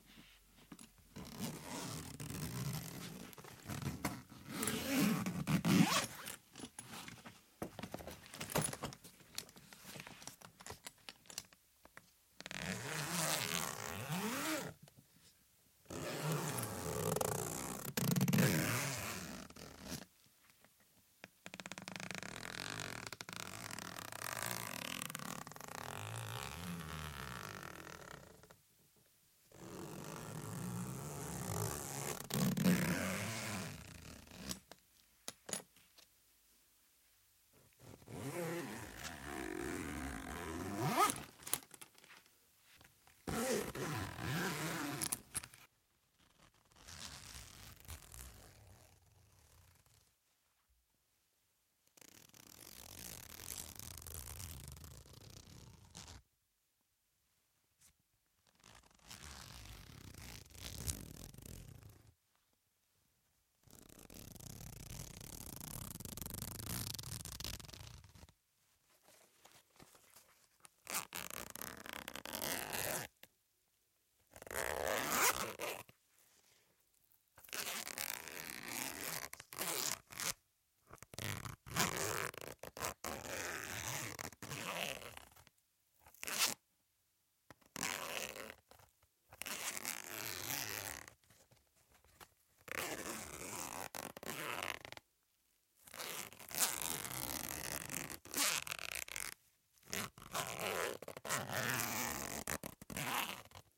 150018 声音设计SFX项目 " 打开手提箱的扣子 OWI
描述：用扣子打开一个金属手提箱
标签： 旅行箱
声道立体声